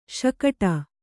♪ śakaṭa